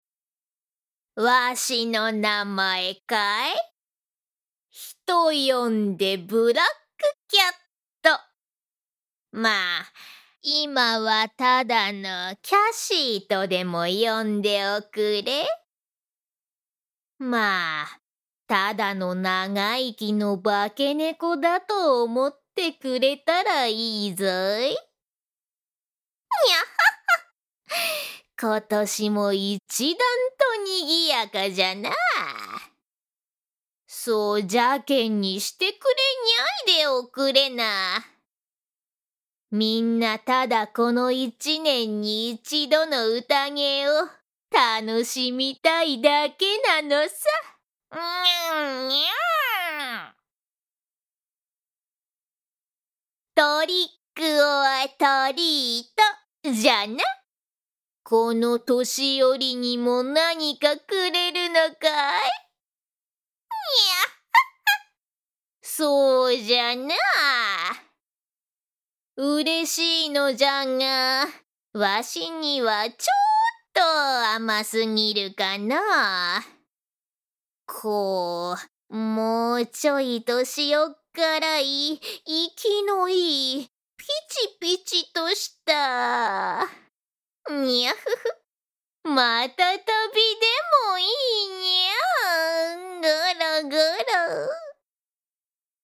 長い時を生きている黒猫さん。
この中で一番年長者みたい